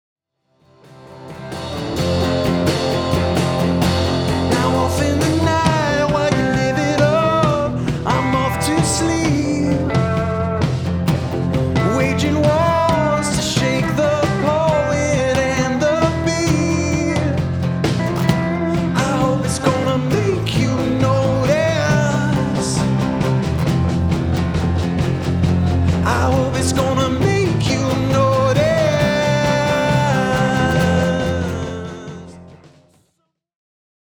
"full band quartet" covers